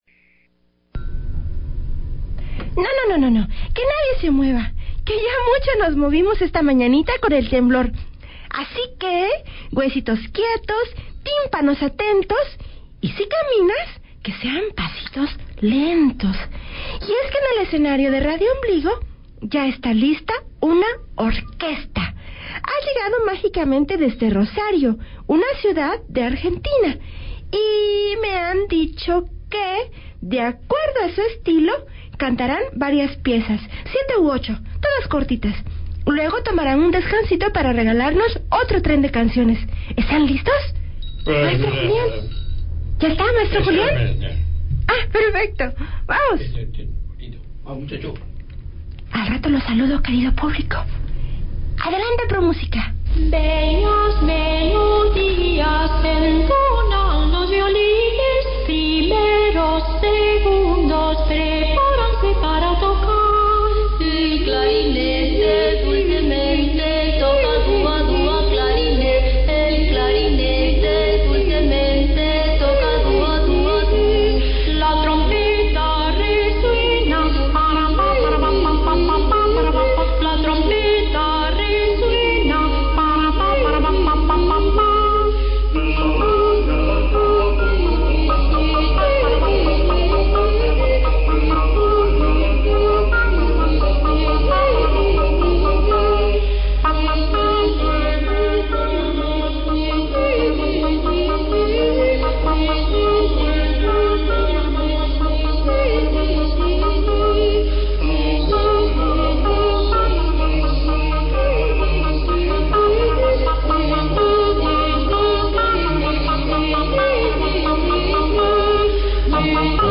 Por la magia de la radio el Pro-Música de Rosario aterriza en la cabina de Radiombligo para regalarnos ocho granitos de su abundante arena musical. Y de cómo se machucó doña Eulolia el dedito desconchavadito.